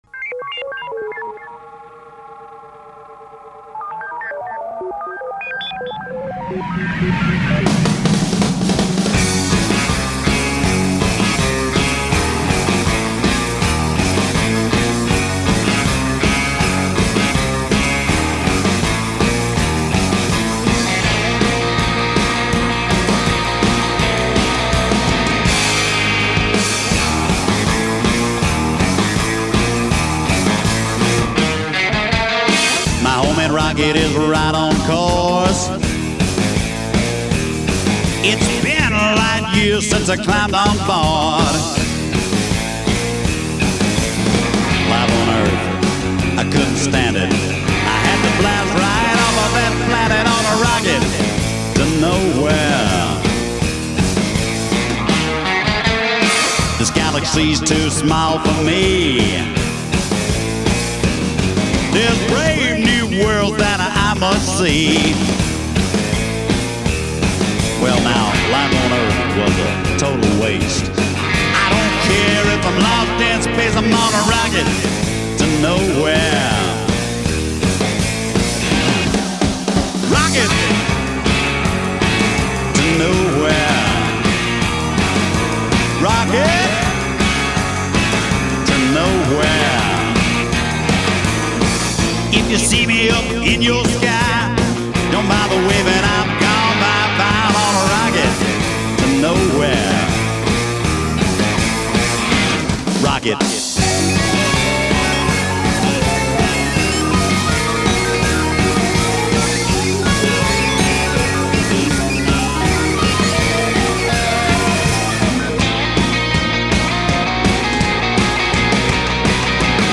Rock and Roll – with a strong side of Outlaw Country